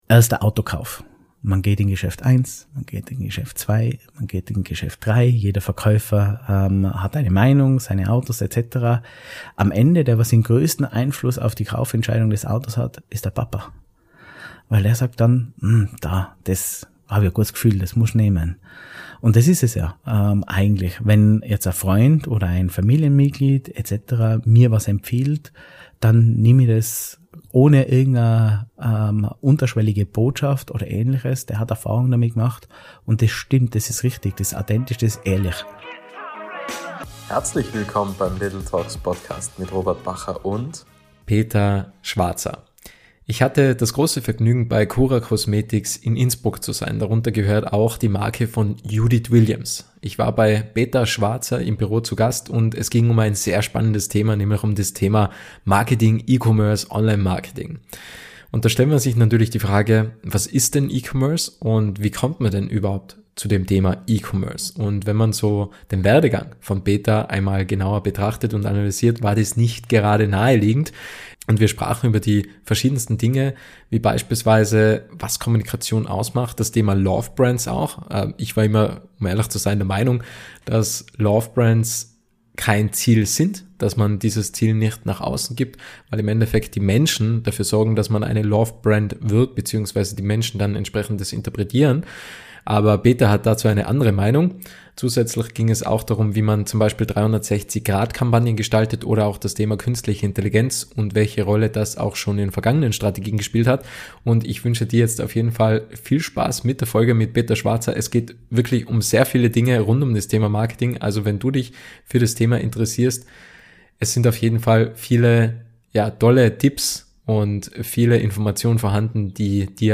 Das Podcast- Format little talks vermittelt in 30-minütigen Interviews spannende Impulse, welche bewegen, zum Nachdenken anregen und Identifikationspotenziale schaffen.